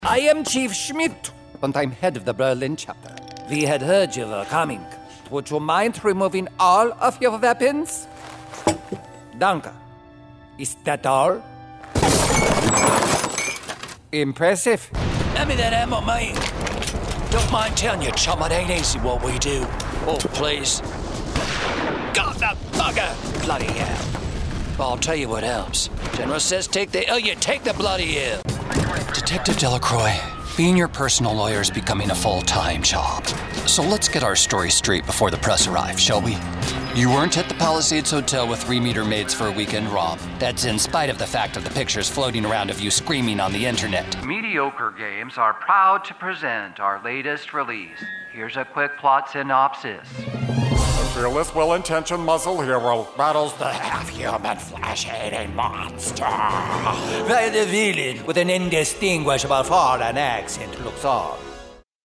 My natural voice is very direct and forthright yet pleasing and approachable. I excel at high energy, multi-charactered voices from urban savvy to downright wacky!
Sprechprobe: Sonstiges (Muttersprache):